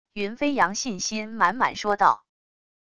云飞扬信心满满说道wav音频